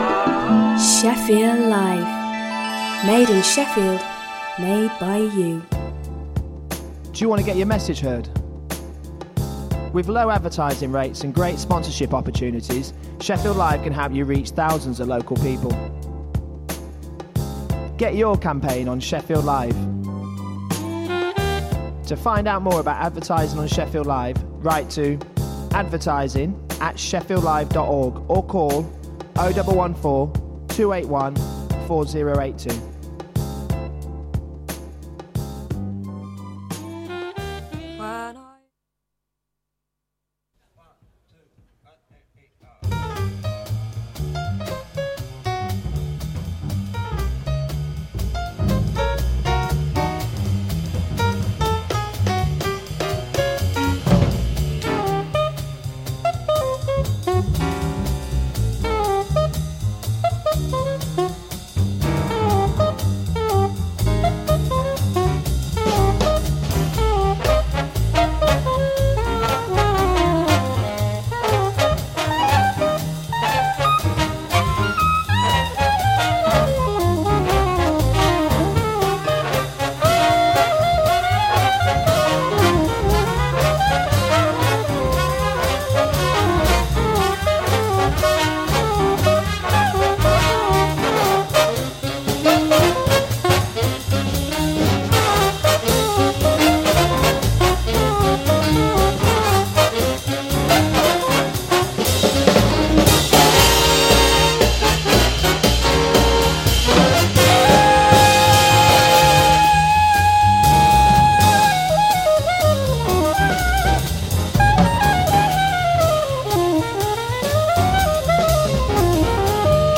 Film and theatre reviews plus swing classics.